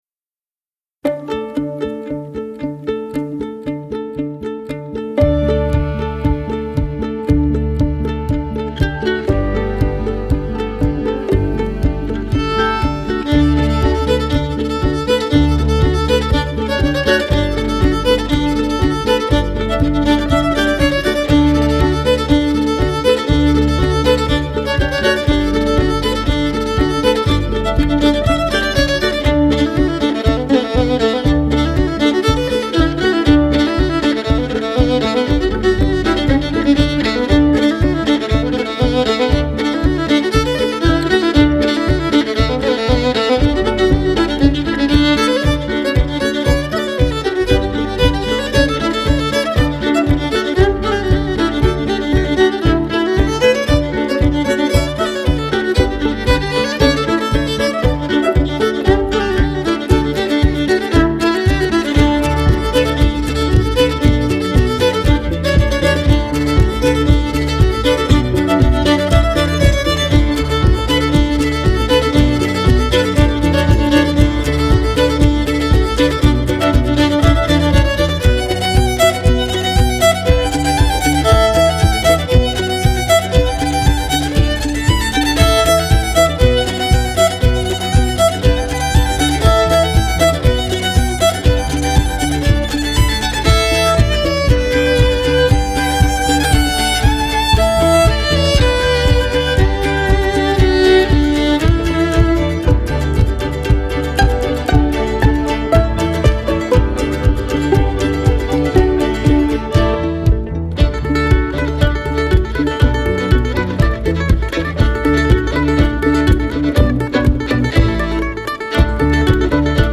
موسیقی اینسترومنتال